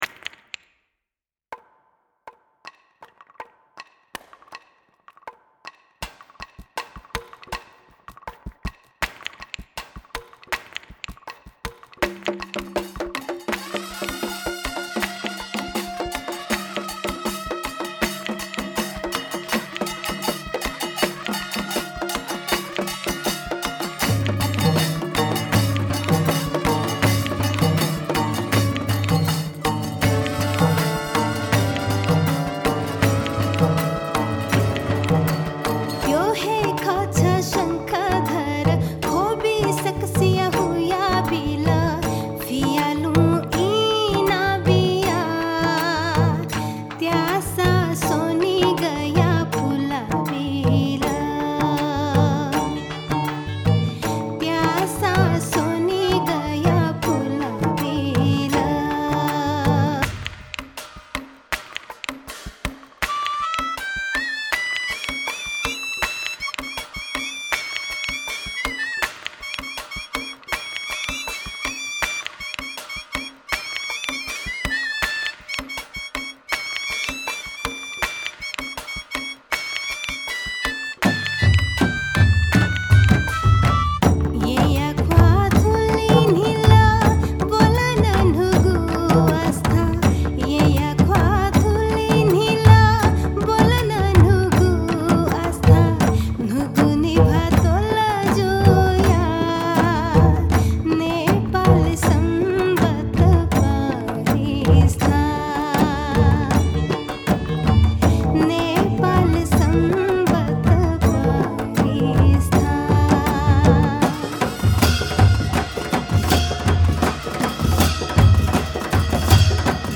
Music of Nepal’s first animated feature film